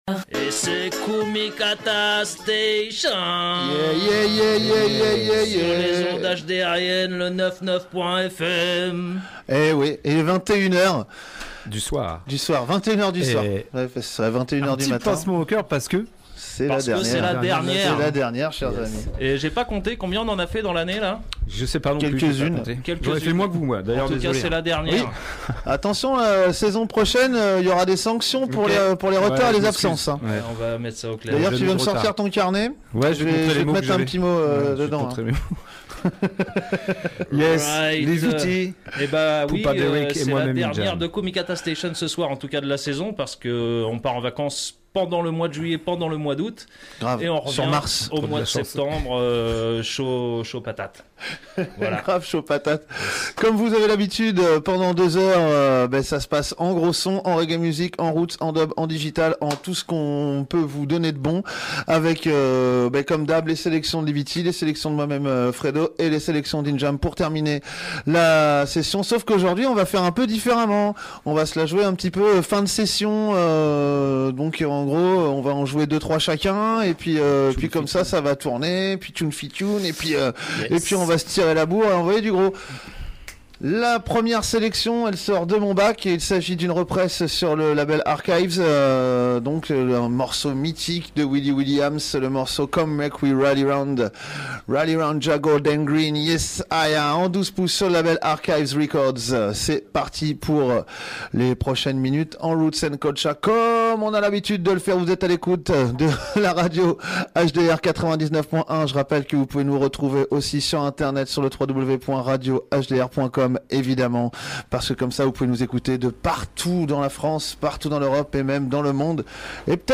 Heavy Roots & Culture Music from the early days to the year 3000 style. Dans une ambiance positive, conviviale et consciente.